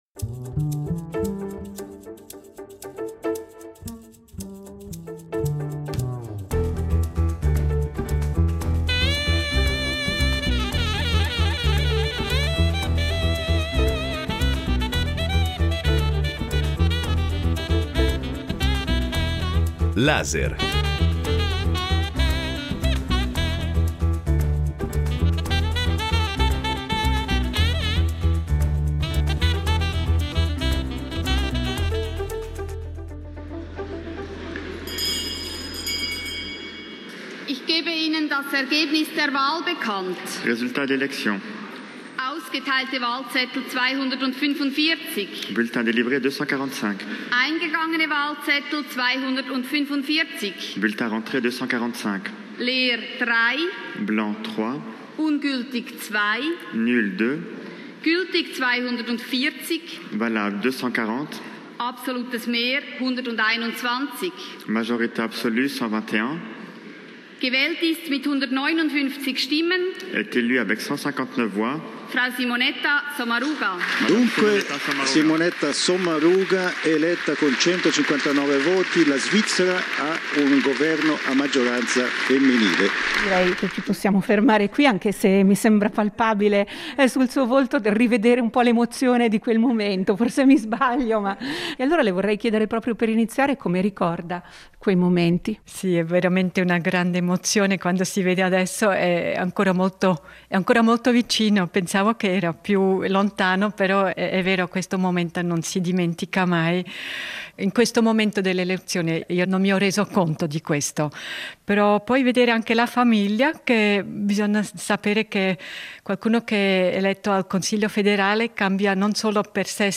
Incontro con Simonetta Sommaruga